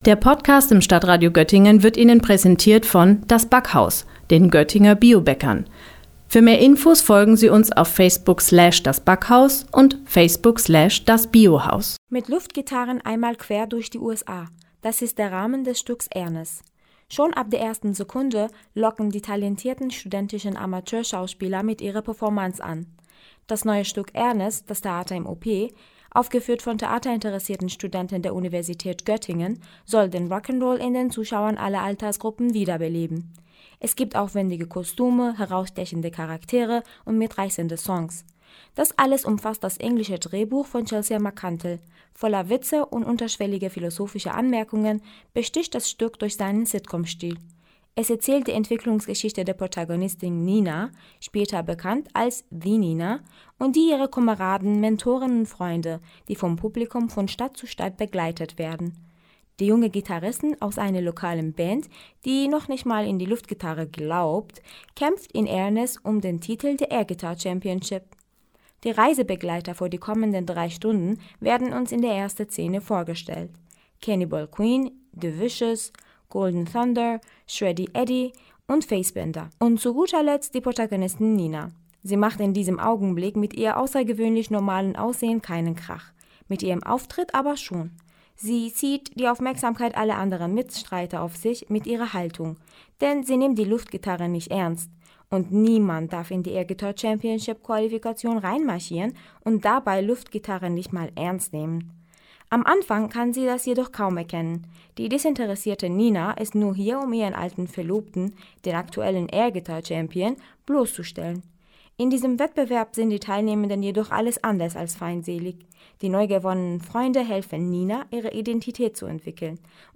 Hören Sie nun, welche Eindrücke sie von dem Stück mitgenommen hat: